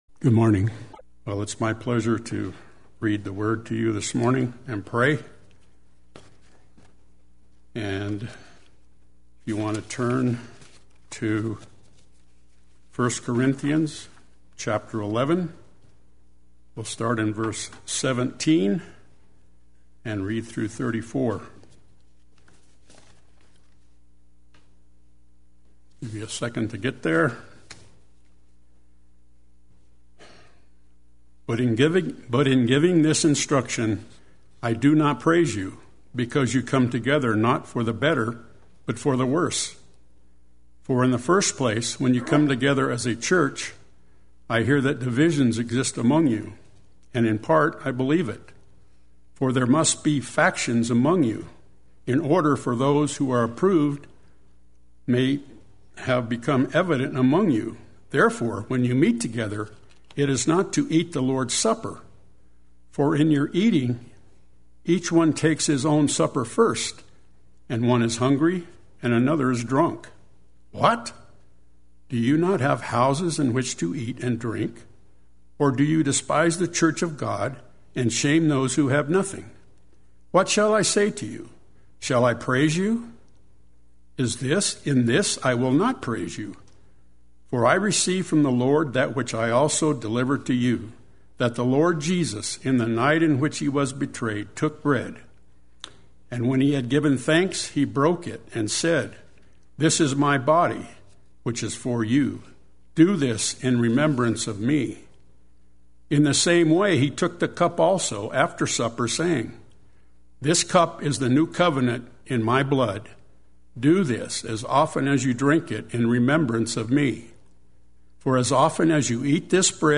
Play Sermon Get HCF Teaching Automatically.
The Lord’s Supper Sunday Worship